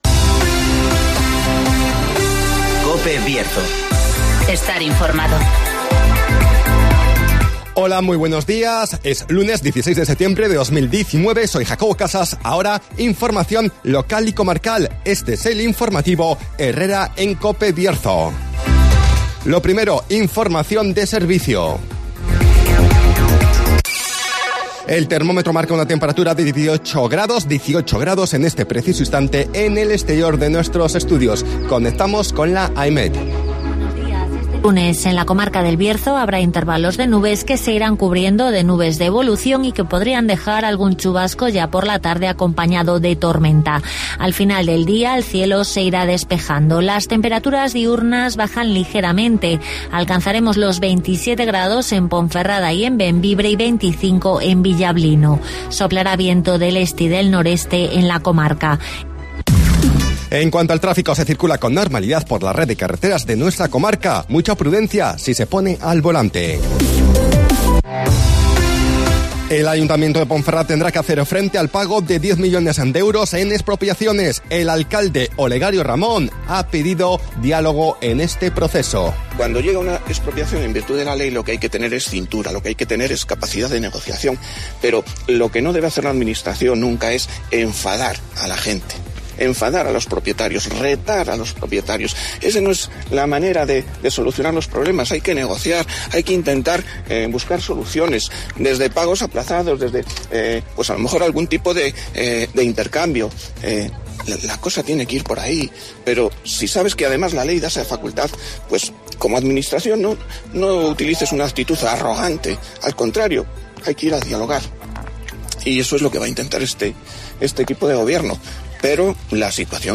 INFORMATIVOS BIERZO
-Conocemos las noticias de las últimas horas de nuestra comarca, con las voces de los protagonistas